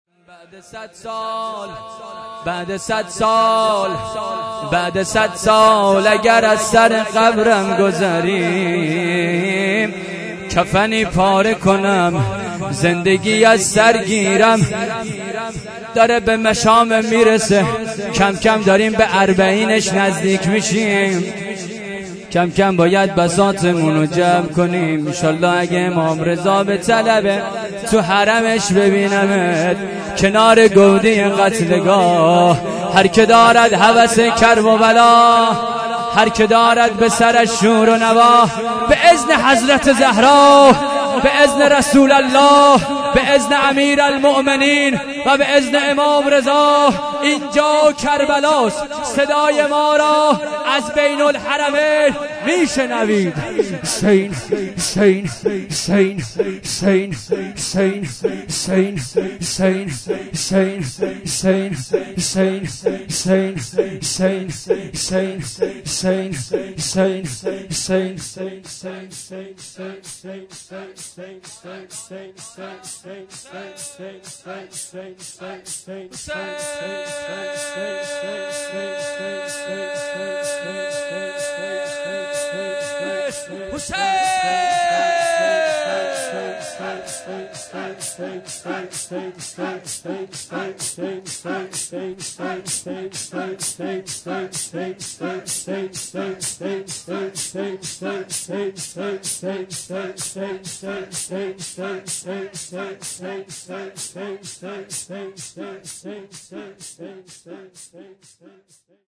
شب‌ پنجم محرم الحرام ۹۷ هیات انصار المهدی (عج)